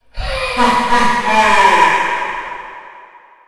evil-laugh-1.ogg